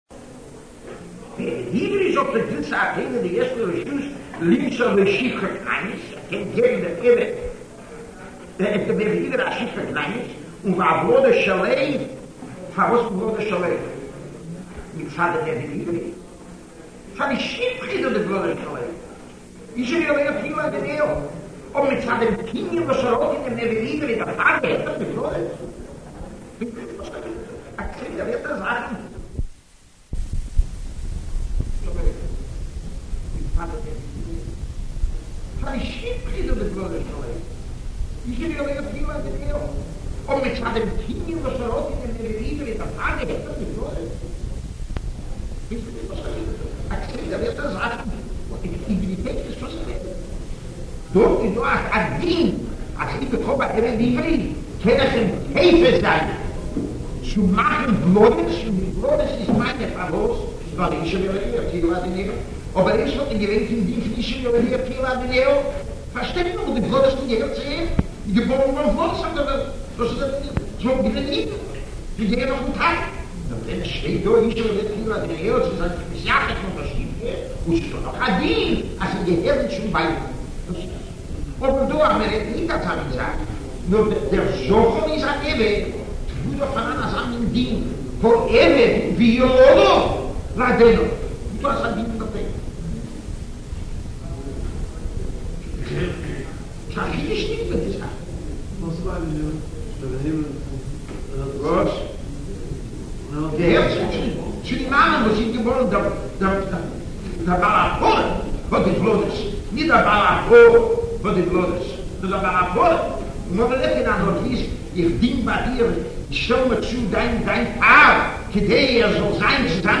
giving a shiur on Minchas Chinuch – Avodim V’Shifchos part III.